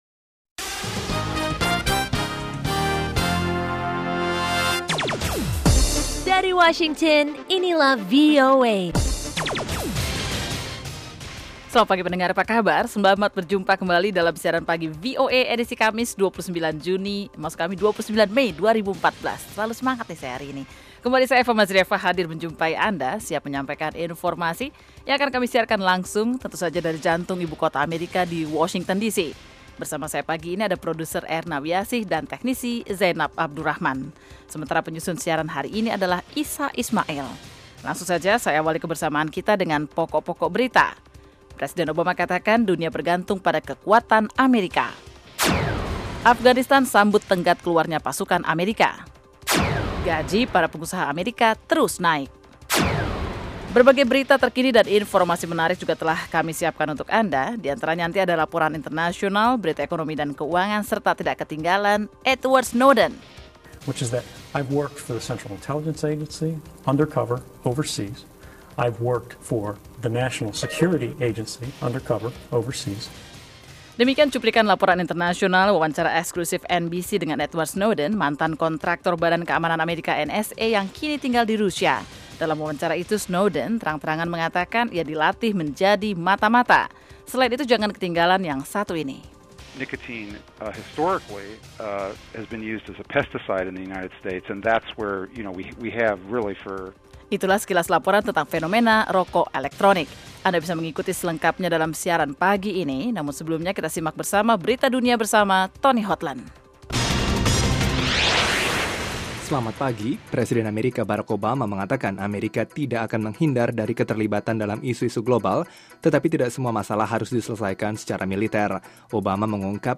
Setiap paginya, VOA menyiarkan sebuah program informatif yang menghidangkan beragam topik yang menarik, berita internasional dan nasional, tajuk rencana, bisnis dan keuangan, olah raga, sains dan kesehatan, musik dan tips-tips pengembangan pribadi.